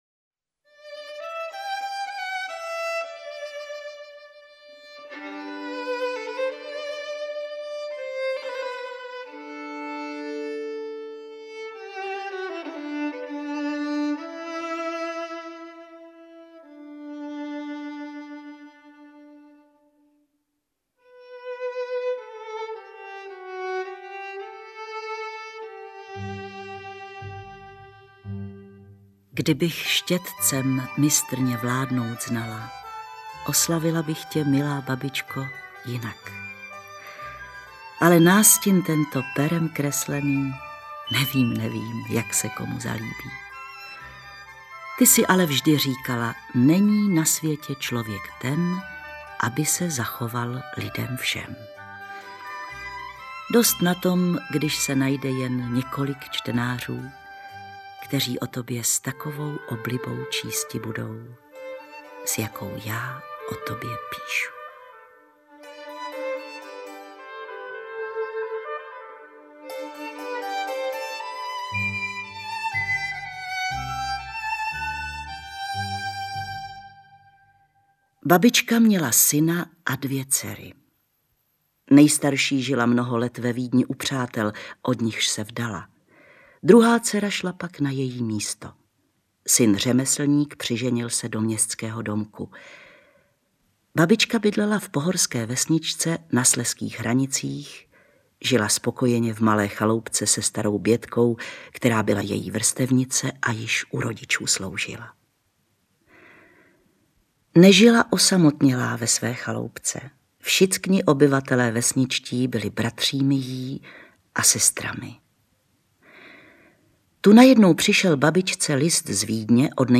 Interpret:  Hana Kofránková